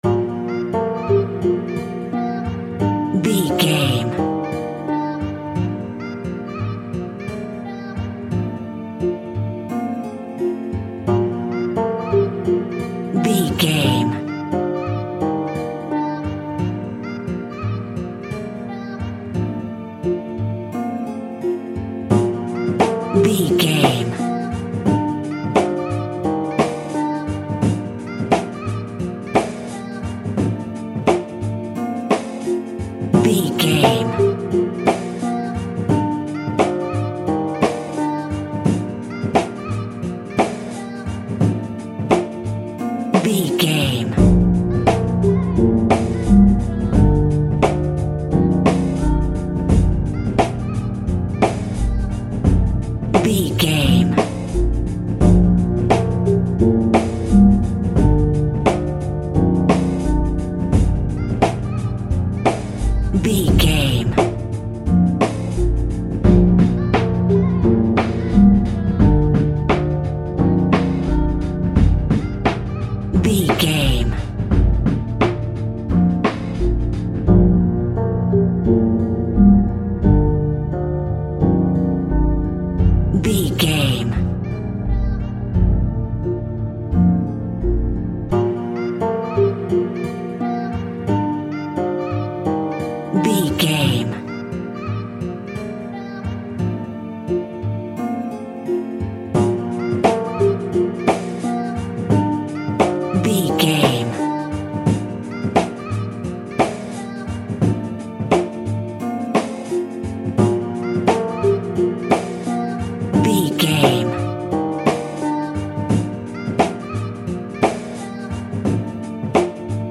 Ionian/Major
drums
dreamy
smooth
calm
mellow